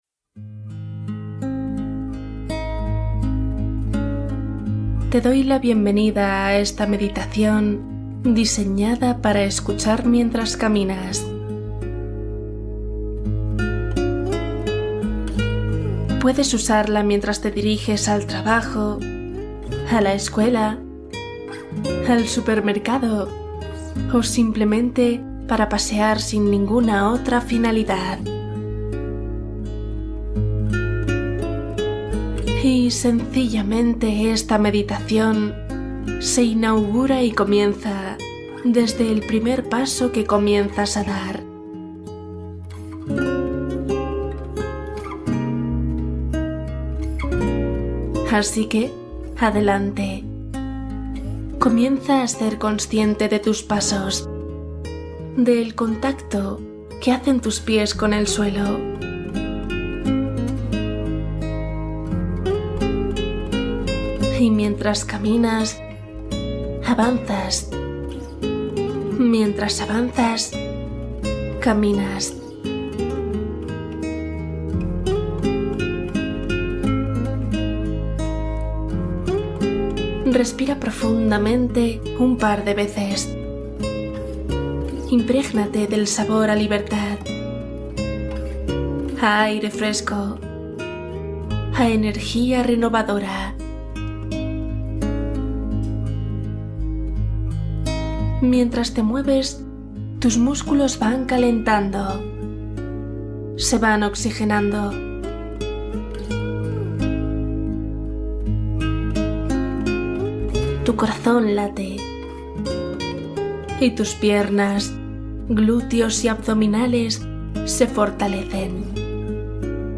Meditación para caminar y relajarte mientras avanzas